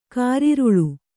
♪ kāriruḷu